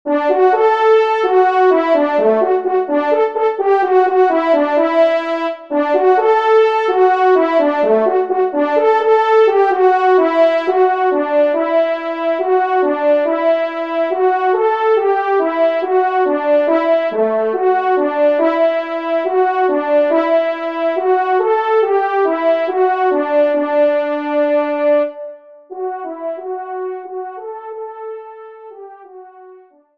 Genre :  Musique Religieuse pour Trompes ou Cors en Ré
1e Trompe